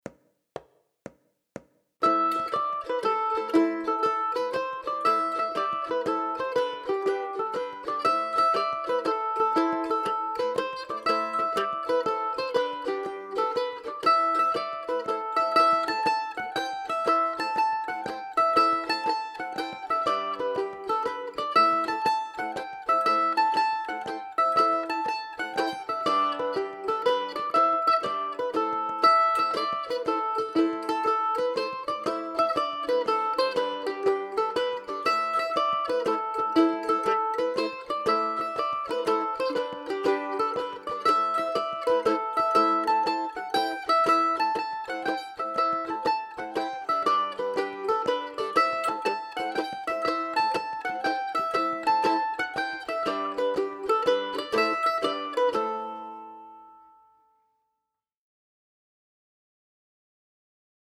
MANDOLIN SOLO Celtic/Irish, Mandolin Solo
DIGITAL SHEET MUSIC - MANDOLIN SOLO